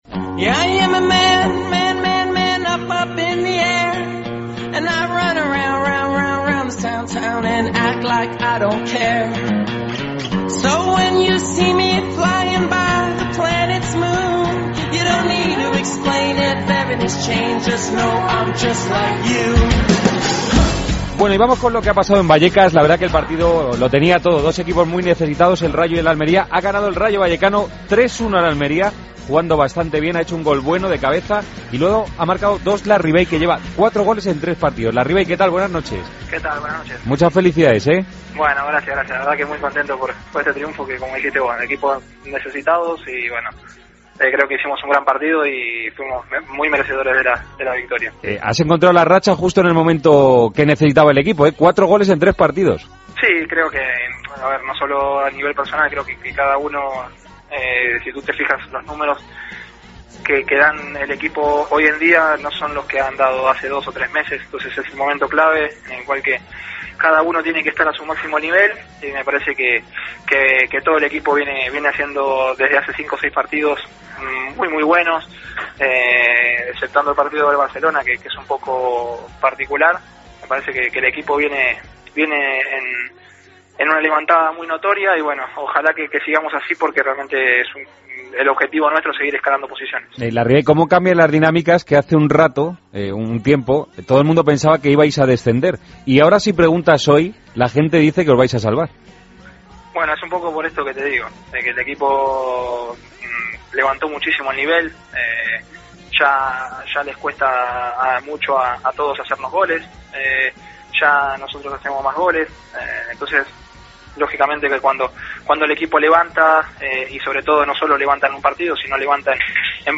Resto de noticias de la Liga BBVA. Entrevistas a Larrivey: "Todos creemos que nos podemos salvar", y Nolito: "Estoy muy contento y feliz en este club".
Con Paco González, Manolo Lama y Juanma Castaño